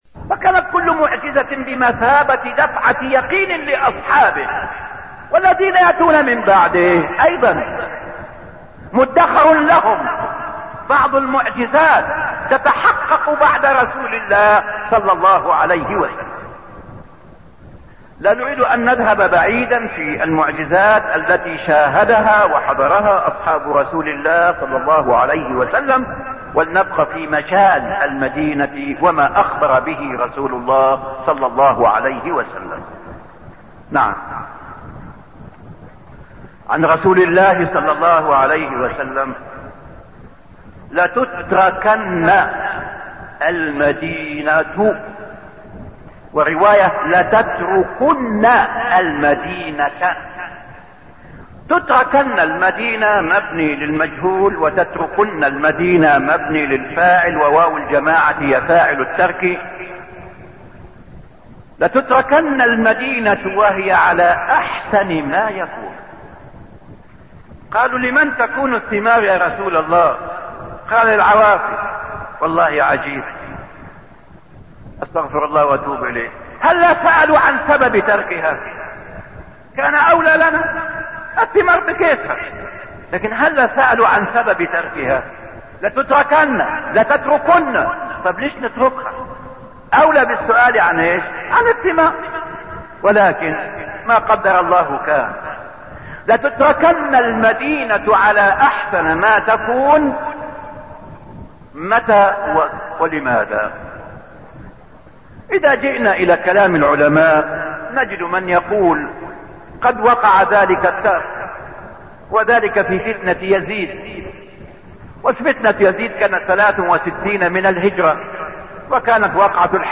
شرح كتاب الجامع من موطأ الإمام مالك وفيه: من قوله: "ما جاء في تحريم المدينة"
المكان: المسجد النبوي